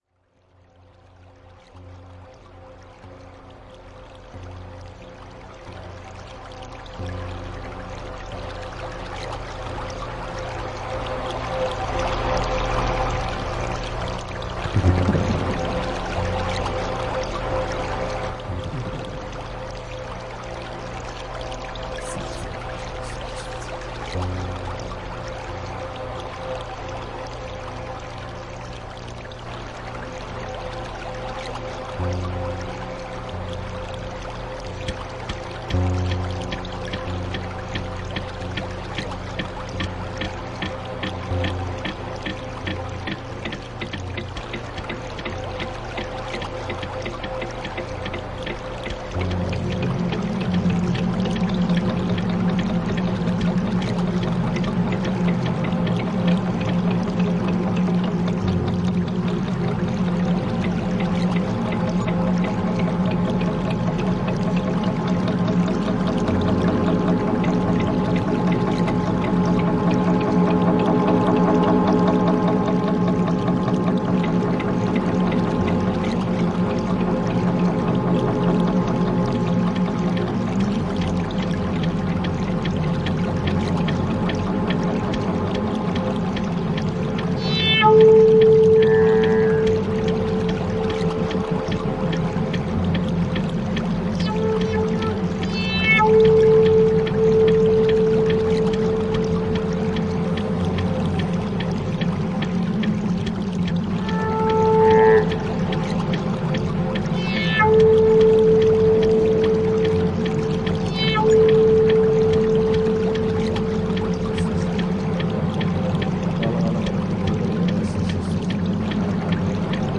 Flute
Synth modular